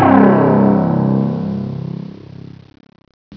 BASS2.WAV